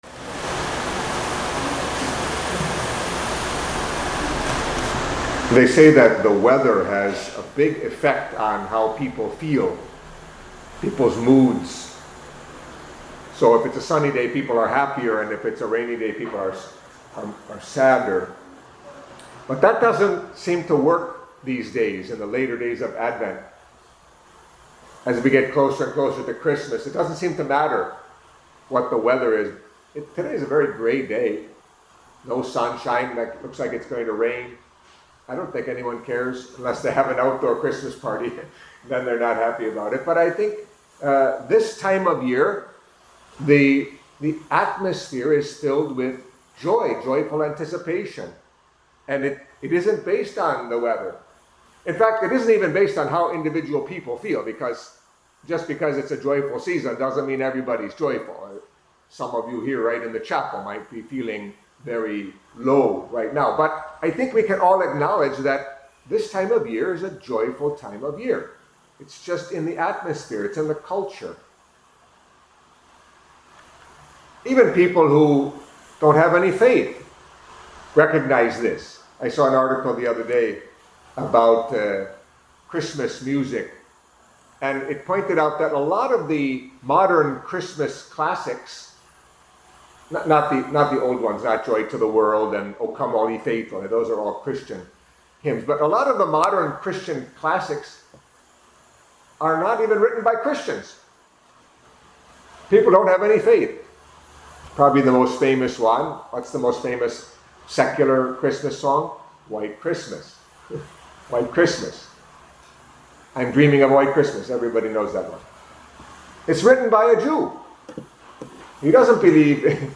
Catholic Mass homily for Saturday of the Third Week of Advent